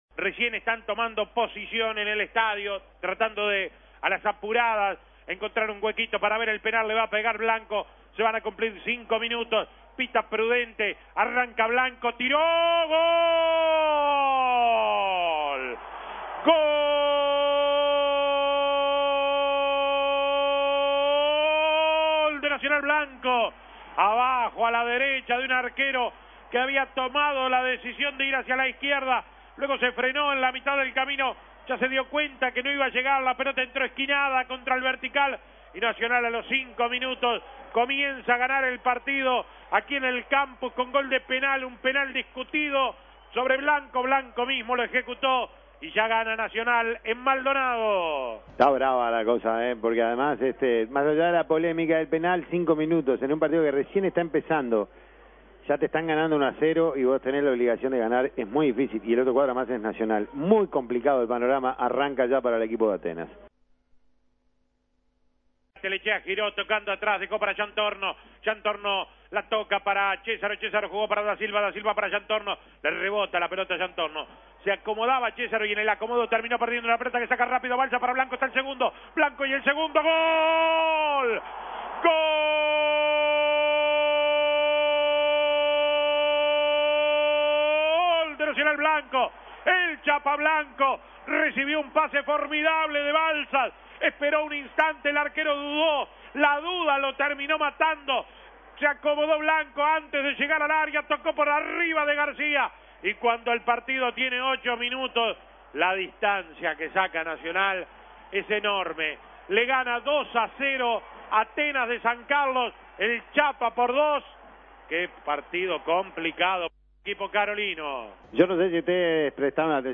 Goles y comentarios ESCUCHE LA GOLEADA DE NACIONAL SOBRE ATENAS Imprimir A- A A+ Nacional aplastó a Atenas 6-0 y mantuvo el liderazgo de la tabla anual.